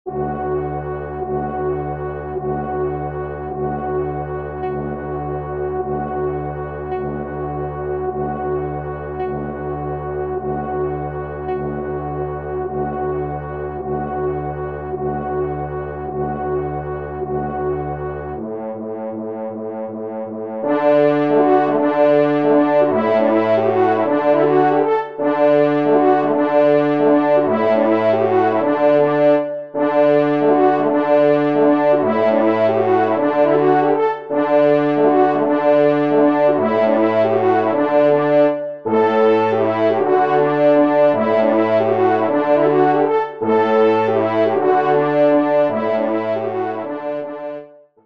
Danses polonaises
5e Trompe